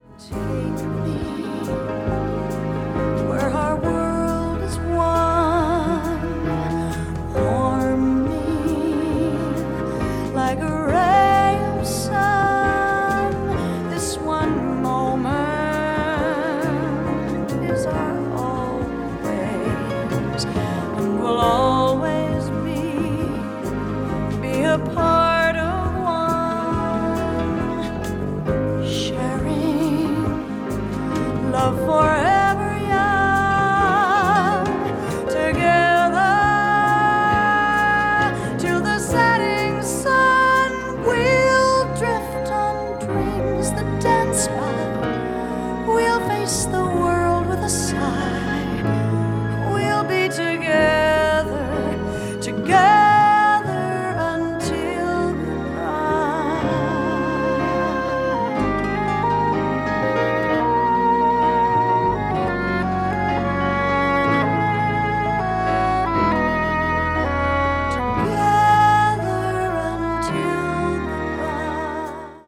a mix of synth-based suspense tracks and catchy pop rhythms
low growling drones of a Moog synthesizer